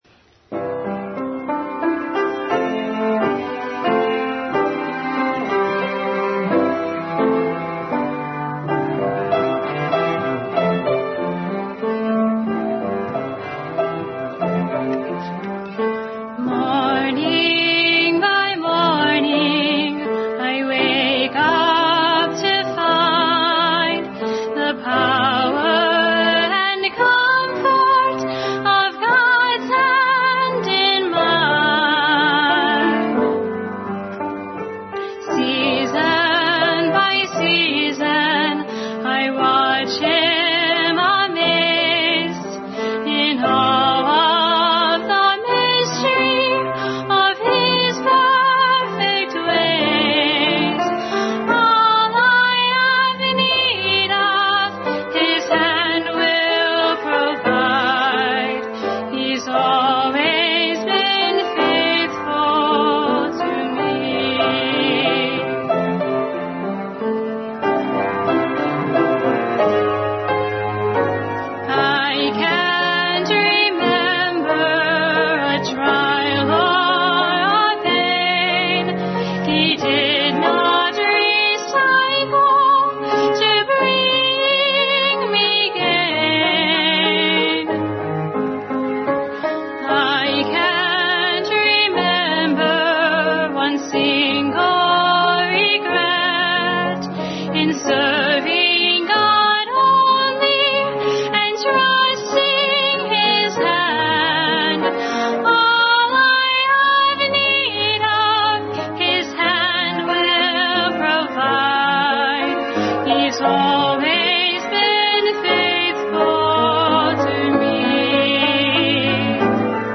If My People – Preceded by Special Music
Bible Text: 2 Chronicles 7:11-14, Revelation 3:14-22 | Family Bible Hour
Sermon